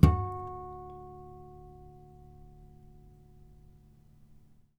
harmonic-07.wav